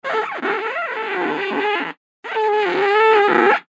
Gemafreie Sounds: Spielzeug
mf_SE-6339-rubber_squeaks_down_up.mp3